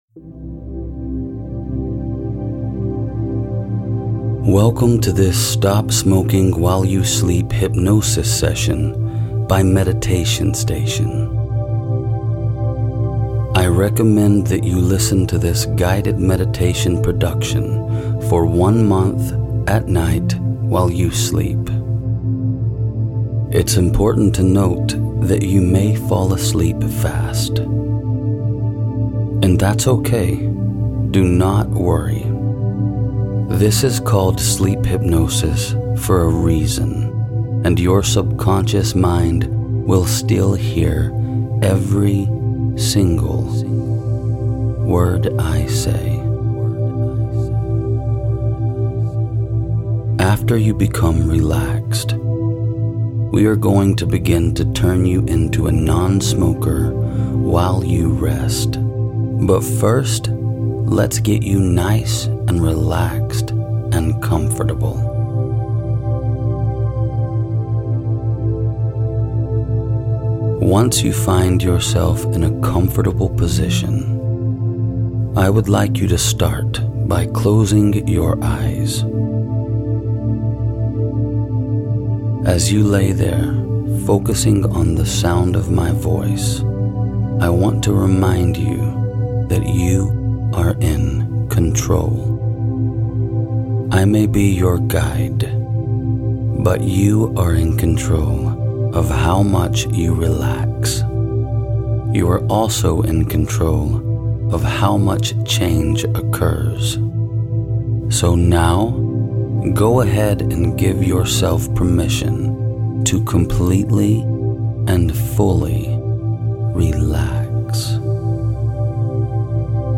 This sleep hypnosis session helps you stop smoking and free yourself.